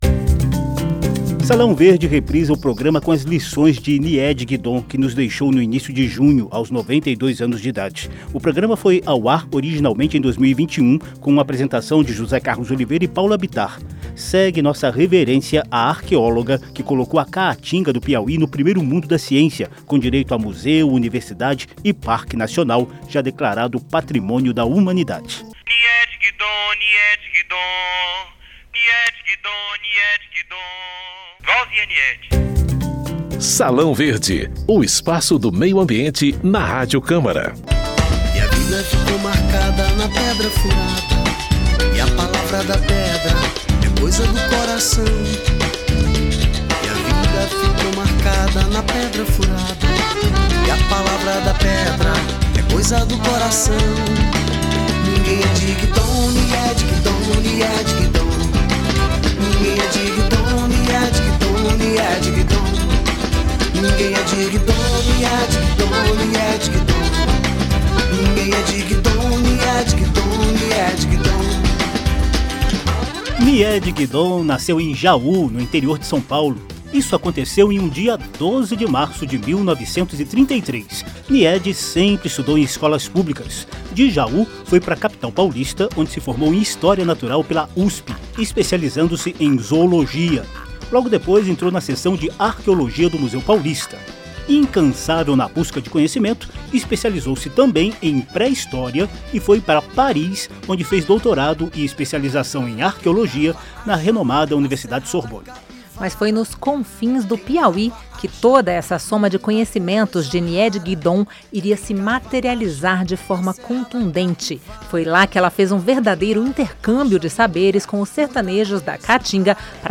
Salão Verde homenageia a arqueóloga Niède Guidon, que nos deixou em 4 de junho, aos 92 anos de idade. Na voz da própria Niède, o programa revive a saga da cientista que saiu do interior de São Paulo para revelar ao mundo as riquezas arqueológicas, ecológicas, paleontológicas, históricas e culturais da Serra da Capivara, no sudeste do Piauí.